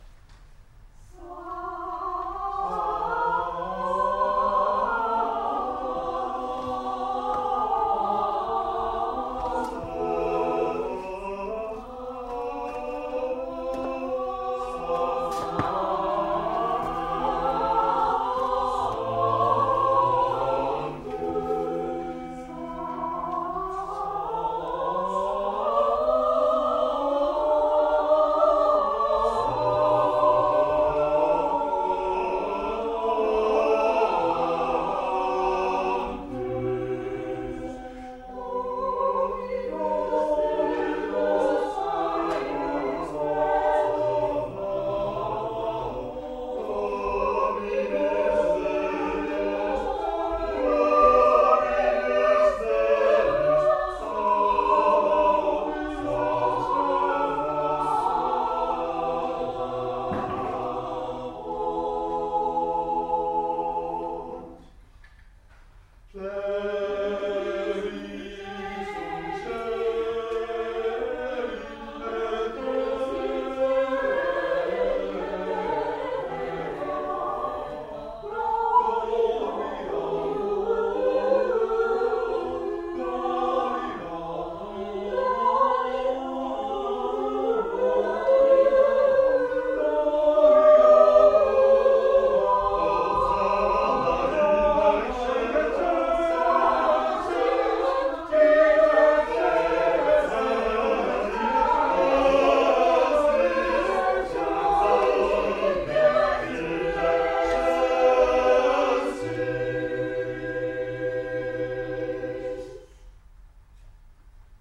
こすもす&野田混声合唱団
ジョイントコンサート
いちいのホール